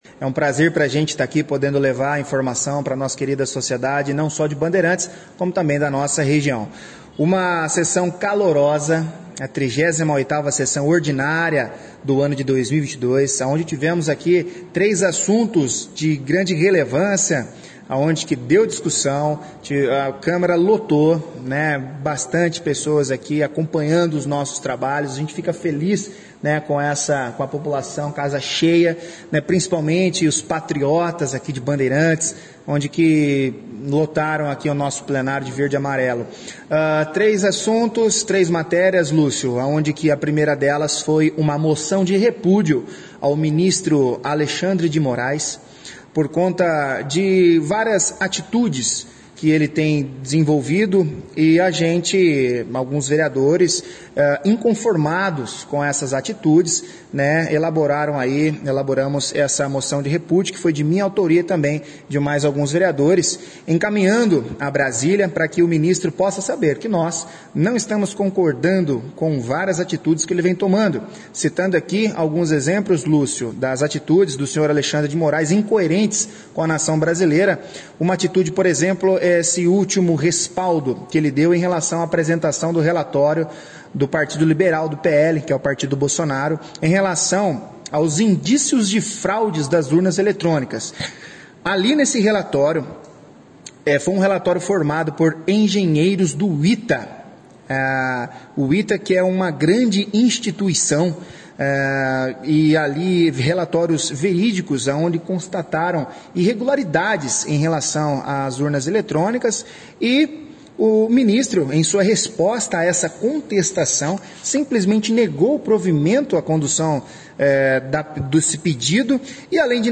A sessão foi destaque da 1ª edição do jornal Operação Cidade com a participação do presidente da Casa Vereador Manoel Affonso Pirolla Vieira